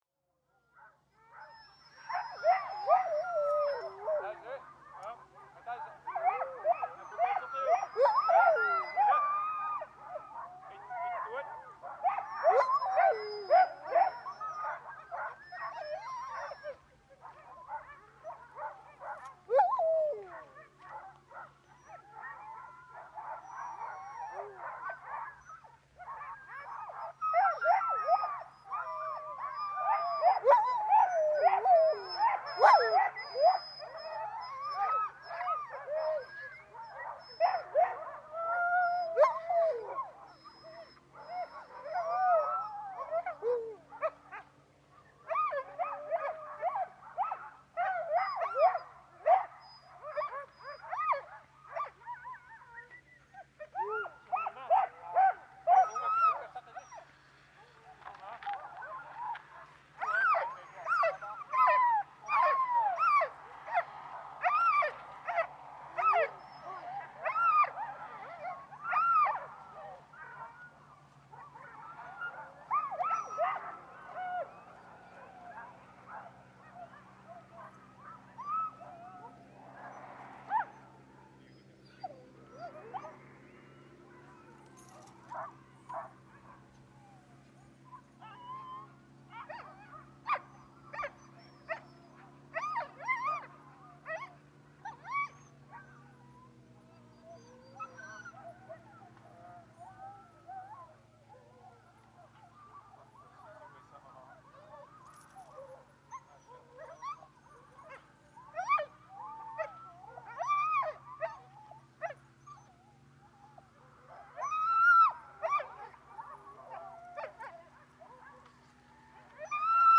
In this recording you have a typical soundscape of Sisimiut’s “dogtown” during winter. Amidst the sound of recreational snowmobilers and dogs howling and pulling at their chains, two Greenlanders are preparing traces and getting their pack of dogs ready to go dogsledding in the backcountry. 4,500 years ago, the Inuit migrated from Canada to Greenland across the sea ice by dog sledge. Modern Greenlanders still use sled dogs for hunting and winter transportation, but the traditional ways have been quickly eroded by the introduction of snowmobiles, which are easier to handle, don’t require feeding in summer, and can go further, faster.
Sisimiut is one of the few places where dogsledding is on the rise, and both the howling of dogs and the whine of snowmobiles is characteristic of the winter landscape.